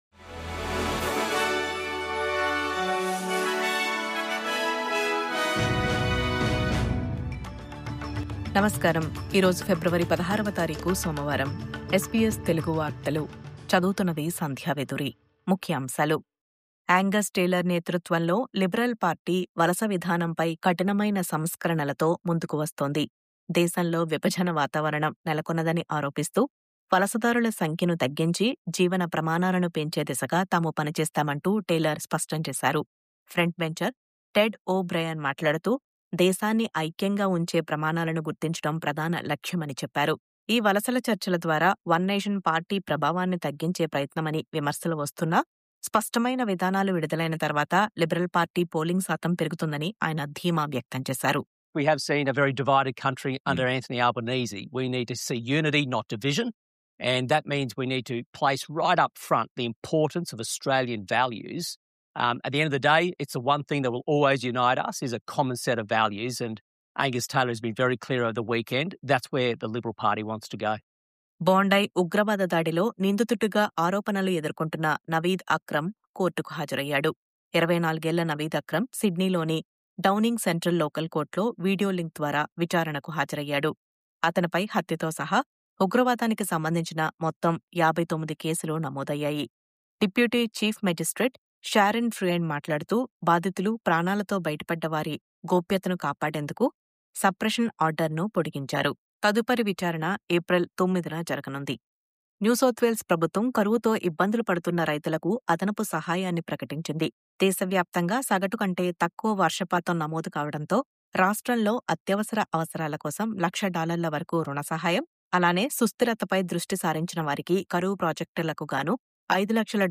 News update: కఠిన వలస విధానాలు...